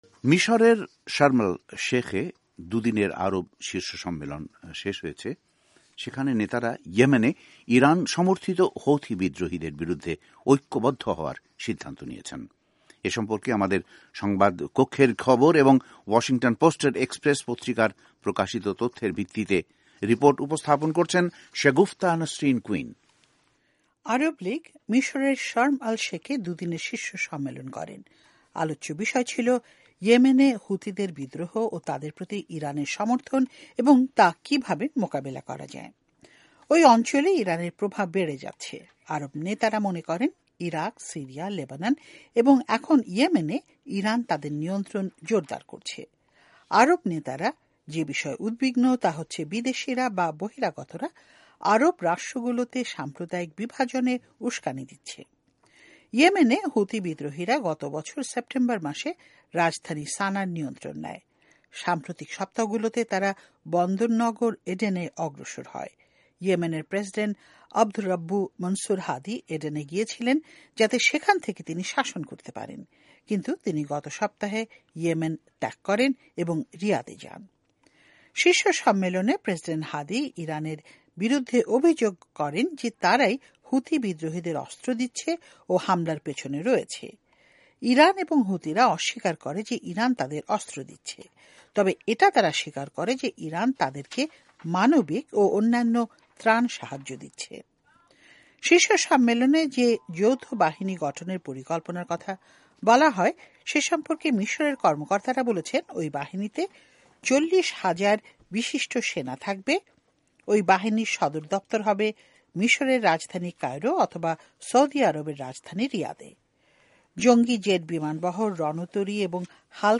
ইয়েমেন সংকটঃ একটি বিশেষ প্রতিবেদন